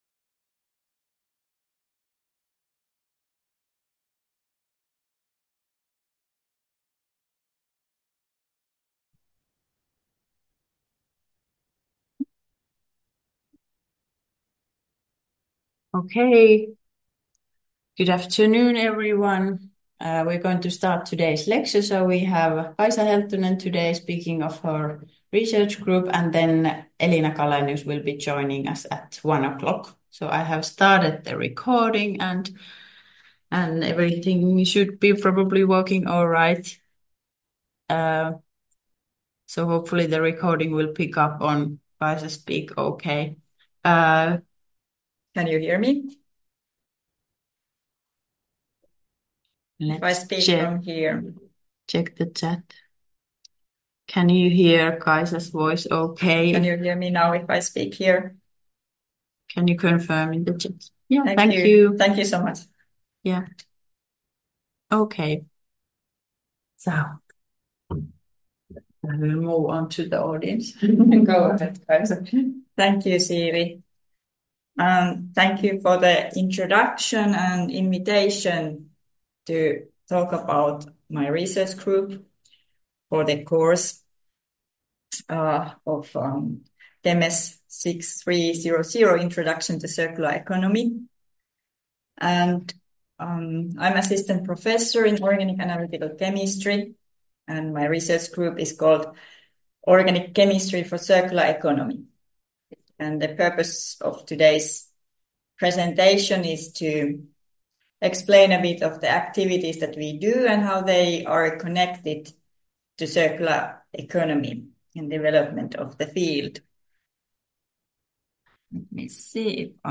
KEMS6300 lecture recording